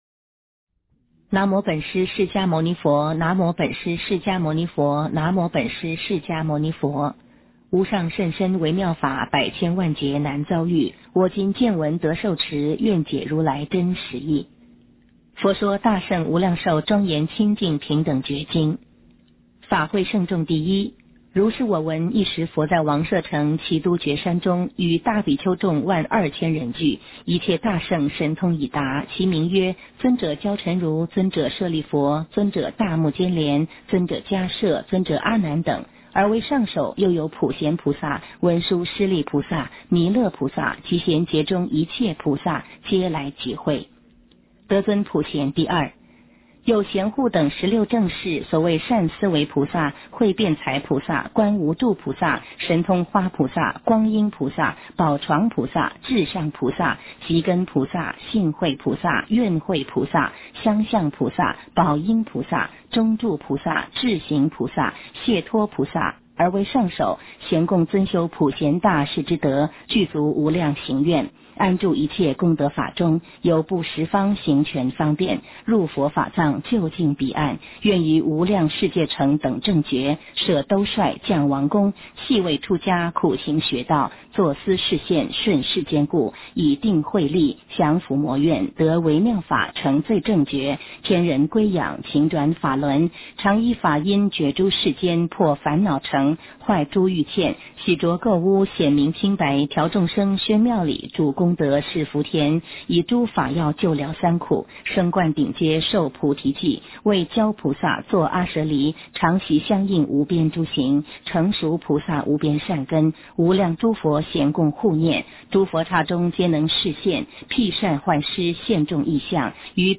佛说大乘无量寿庄严清净平等觉经（女声读诵）
佛说大乘无量寿庄严清净平等觉经（女声读诵） 诵经 佛说大乘无量寿庄严清净平等觉经（女声读诵）--未知 点我： 标签: 佛音 诵经 佛教音乐 返回列表 上一篇： 妙法莲华经 下一篇： 解深密经-2（念诵） 相关文章 六字大明咒(演奏版) 六字大明咒(演奏版)--新韵传音...